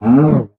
sounds / mob / cow / hurt1.ogg
hurt1.ogg